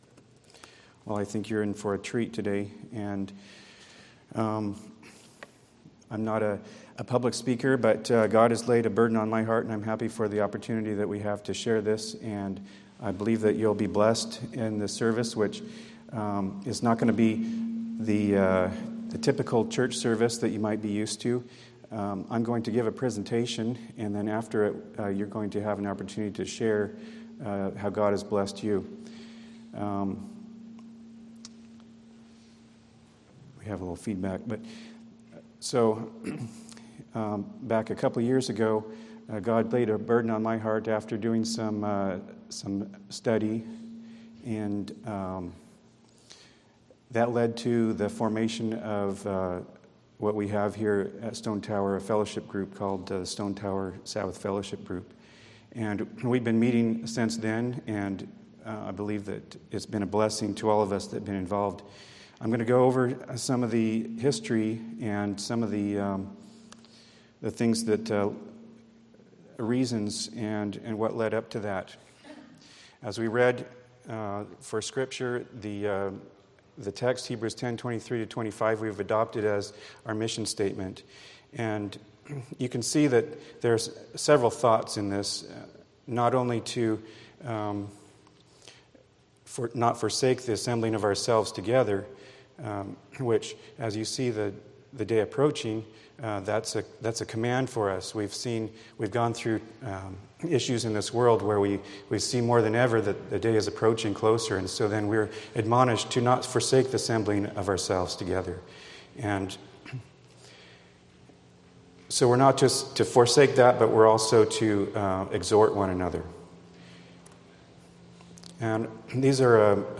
Sabbath Fellowship Group
Sabbath Fellowship Group Tagged with 1888 , Giving Thanks , History , Praise , Social Meeting , Testimony , Three Angels